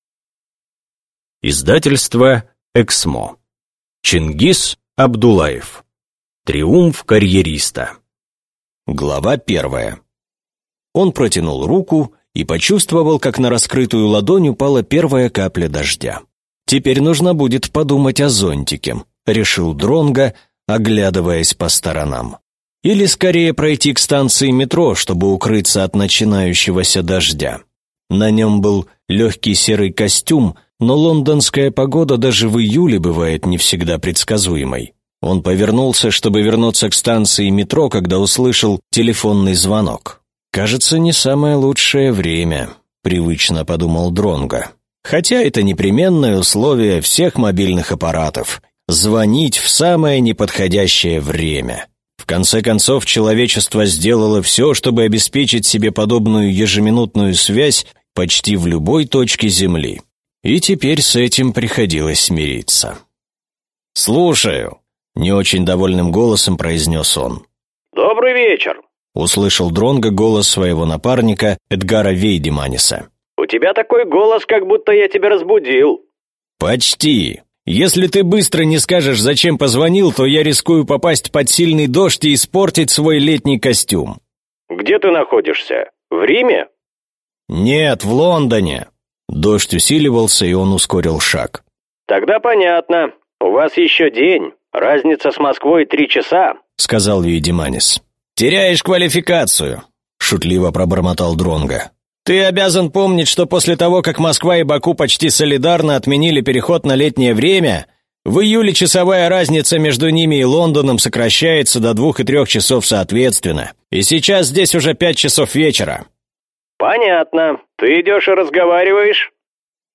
Аудиокнига Триумф карьериста | Библиотека аудиокниг